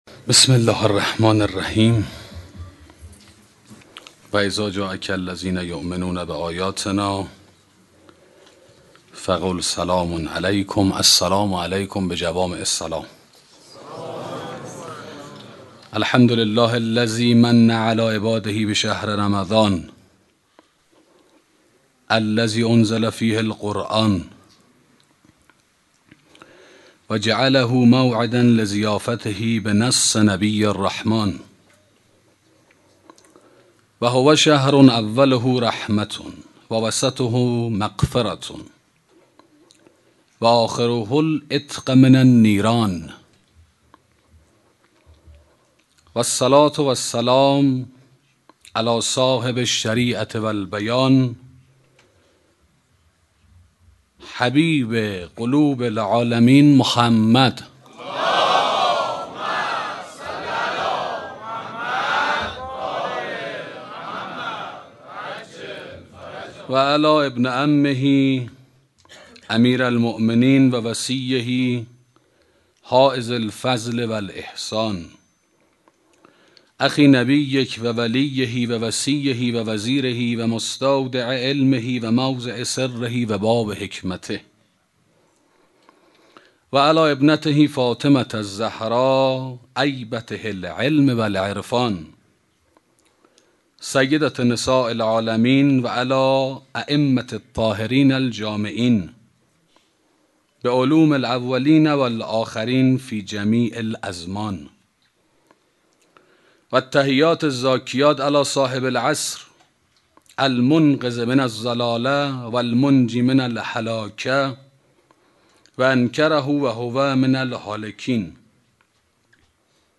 سخنرانی فتوت و جوانمردی